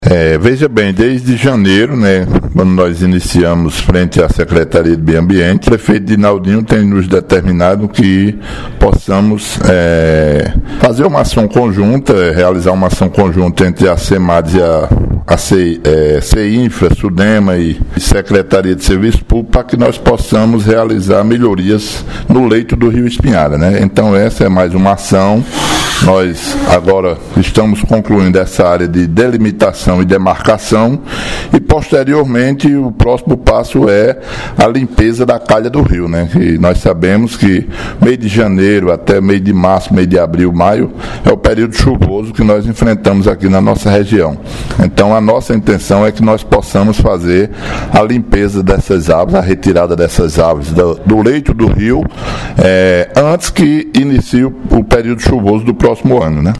• Fala do secretário Municipal de Meio Ambiente, Natércio Alves –